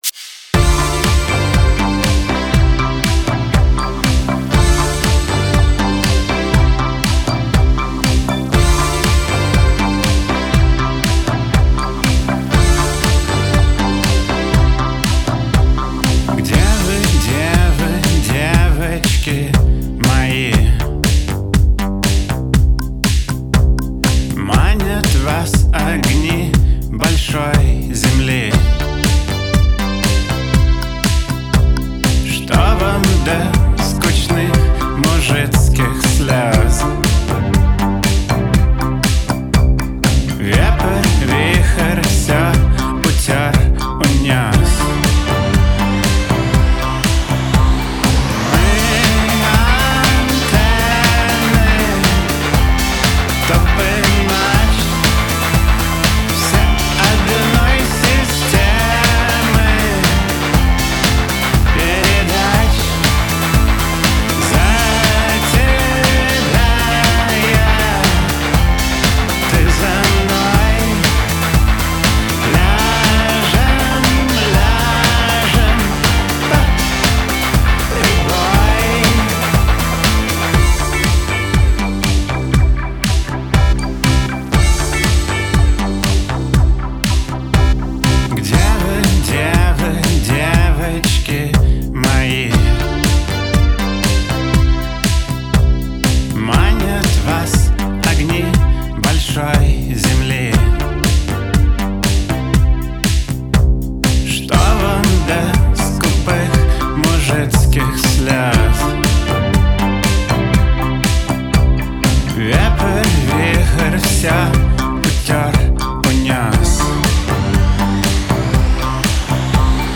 Категория: Rock 2016